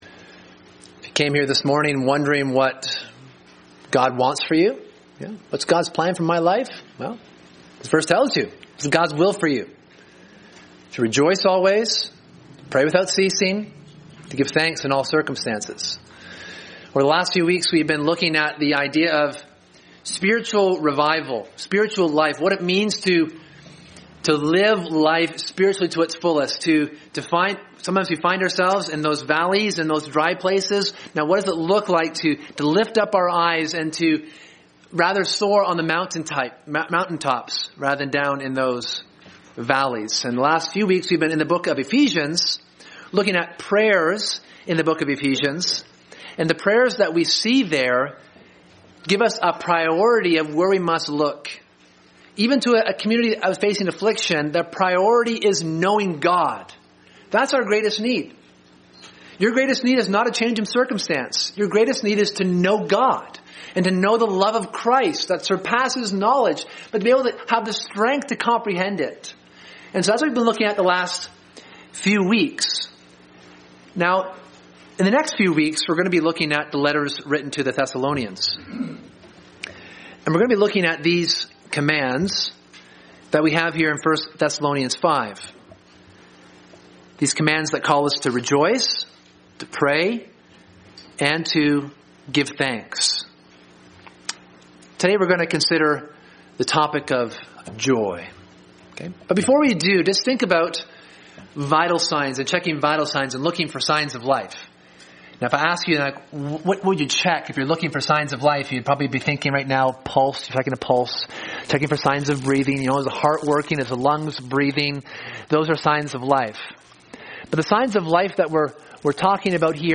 Sermon: A Life of Rejoicing